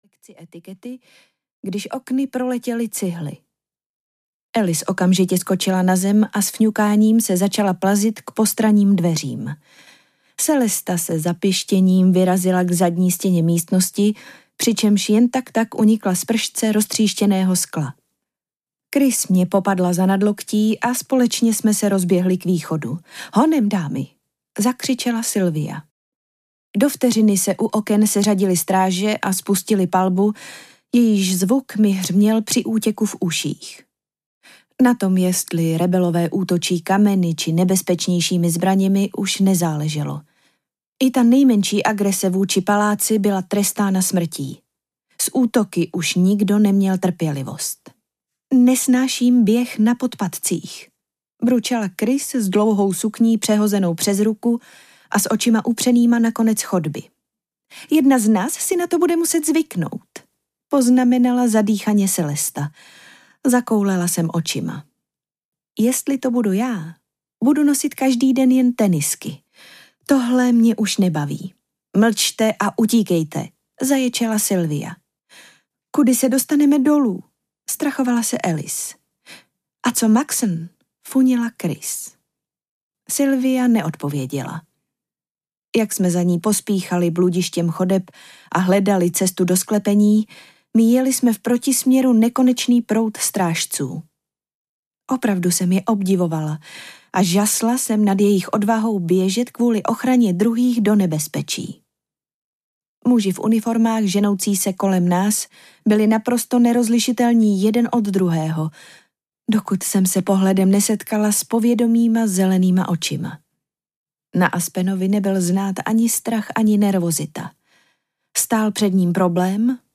První audiokniha
Ukázka z knihy